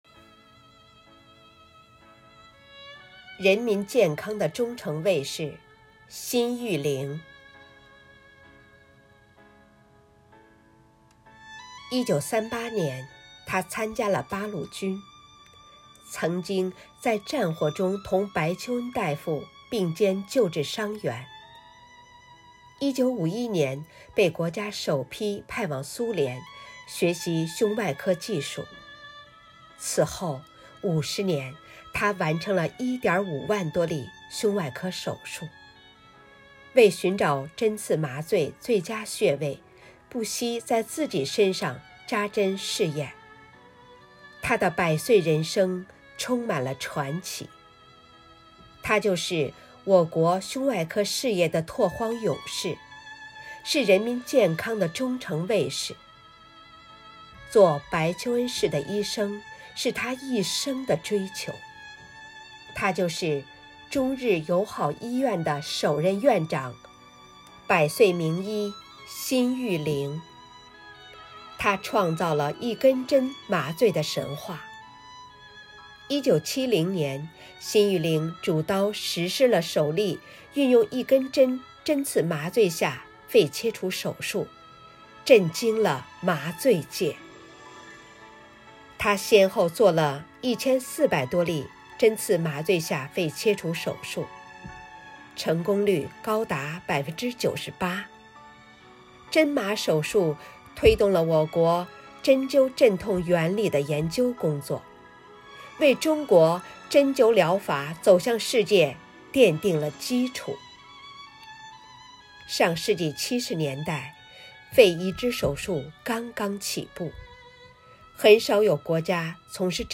五一劳动节来临之际，为致敬最美劳动者，4月28日，生活好课堂幸福志愿者魅力之声朗读服务（支）队举办“致敬最美劳动者 一一我心中的故事”云朗诵会。